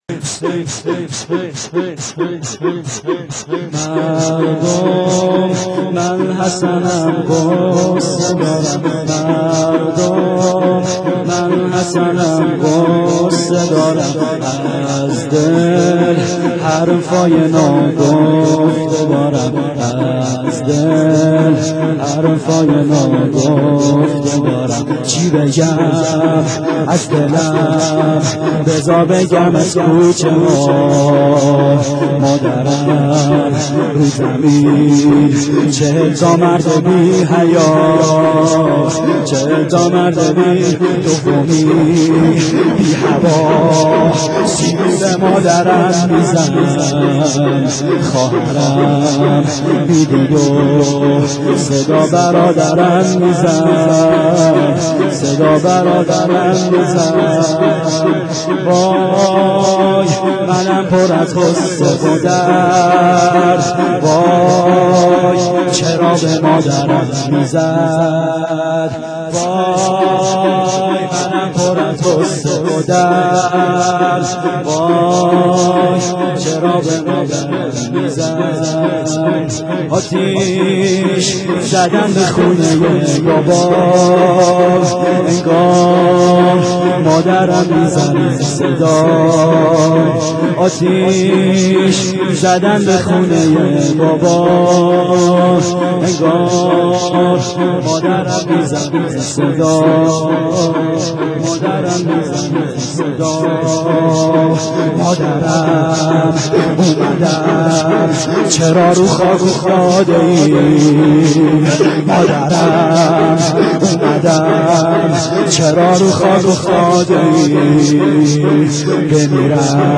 شور سوم